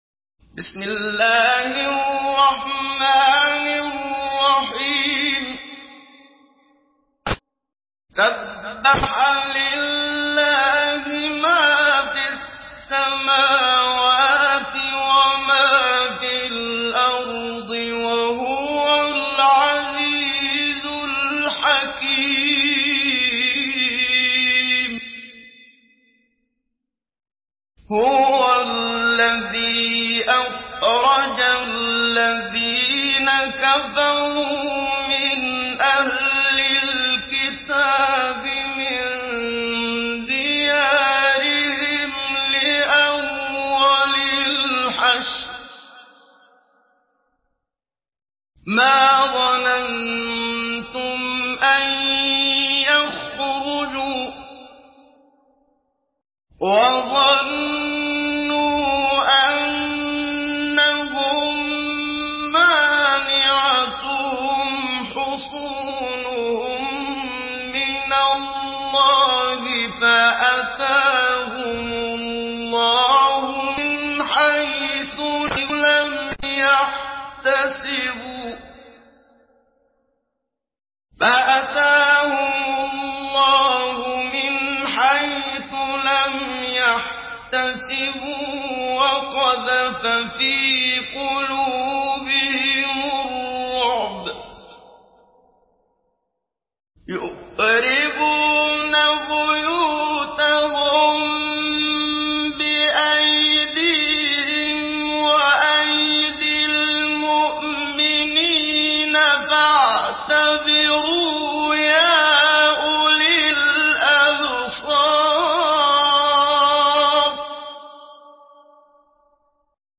Surah Al-Hashr MP3 in the Voice of Muhammad Siddiq Minshawi Mujawwad in Hafs Narration
Surah Al-Hashr MP3 by Muhammad Siddiq Minshawi Mujawwad in Hafs An Asim narration.